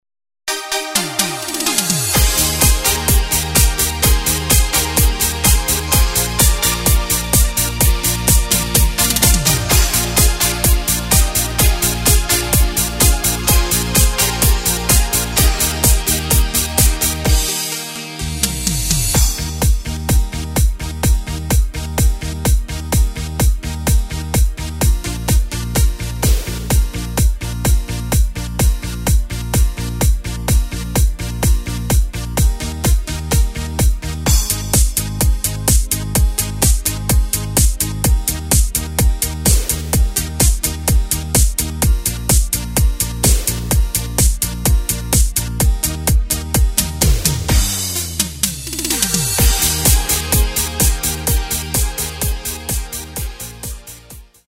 Tempo:         127.00
Tonart:            Eb
Discofox aus dem Jahr 2025!
Playback mp3 Demo